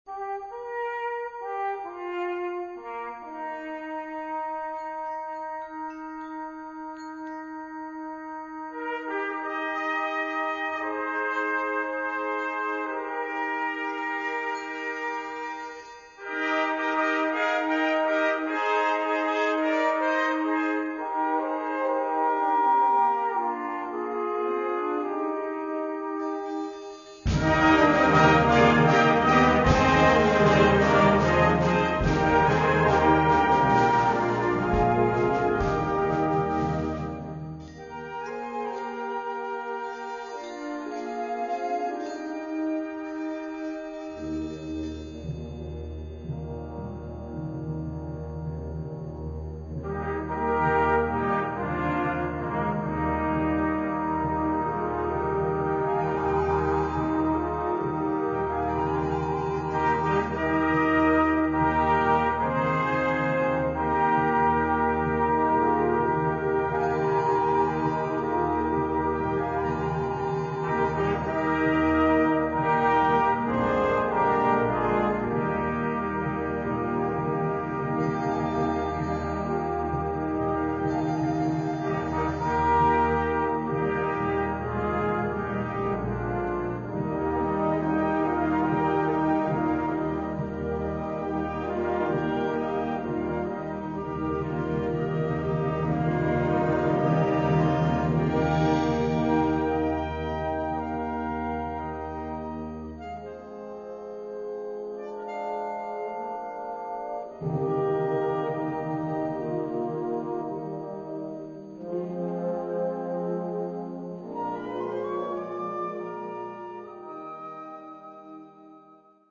Catégorie Harmonie/Fanfare/Brass-band
Sous-catégorie Musique d'Europe de l'est
Instrumentation Ha (orchestre d'harmonie)